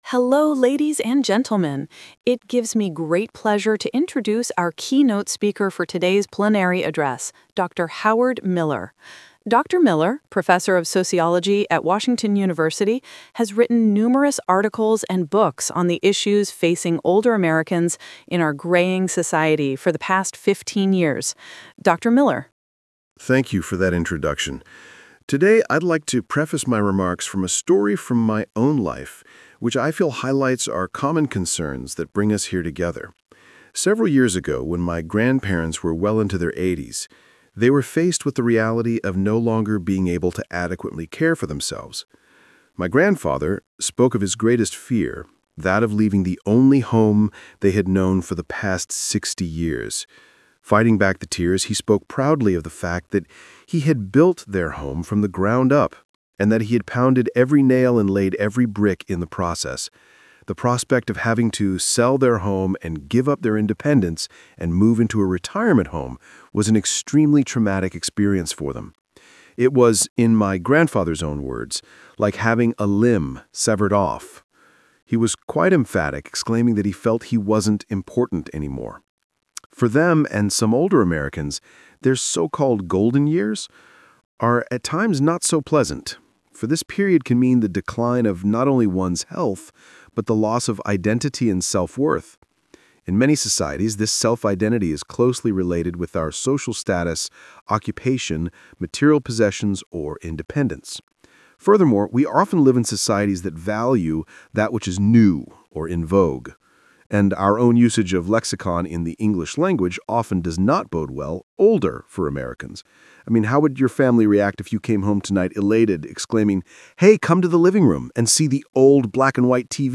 Talk/Lecture 3: You will hear a talk about aging society.